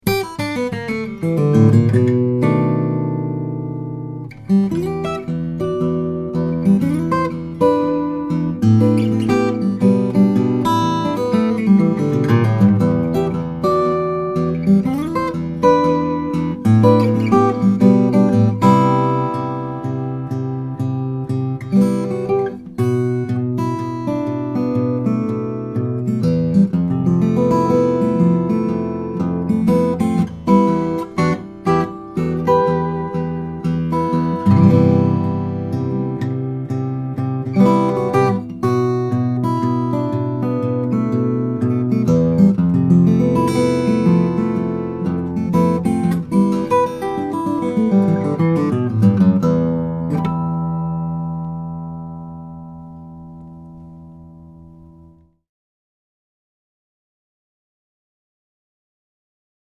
Everyt ime we are impressed with the beautiful construction and details but mostly but the wonderous voice his instruments possess.
Pickup None